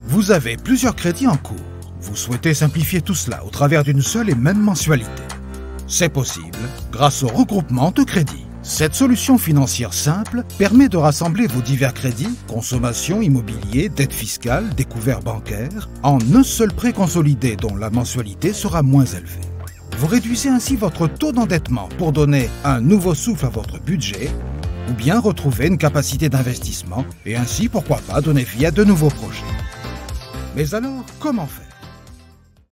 Une voix mature, posée, élégante
Sprechprobe: Industrie (Muttersprache):
A mature and elegant voice